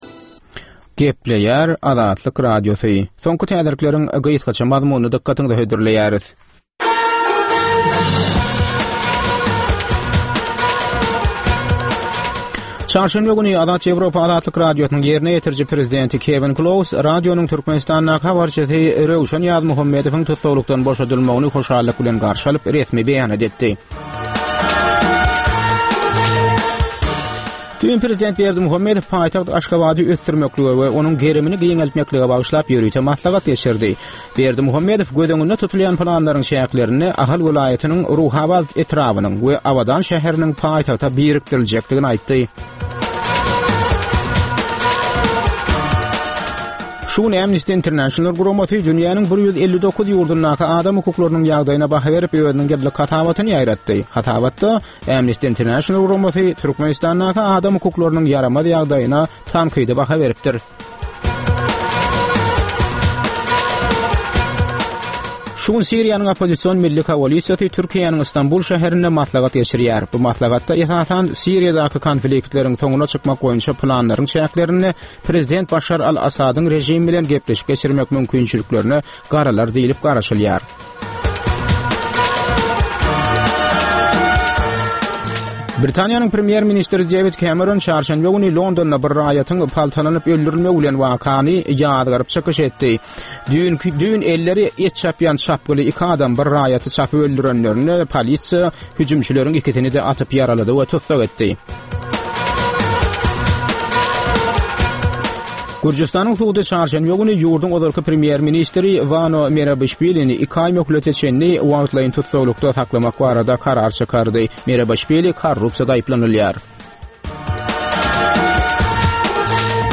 Türkmenistanly synçylaryň gatnaşmagynda, ýurduň we halkyň durmuşyndaky iň möhüm meseleler barada töwerekleýin gürrüň edilýän programma. Bu programmada synçylar öz pikir-garaýyşlaryny aýdyp, jedelleşip bilýärler.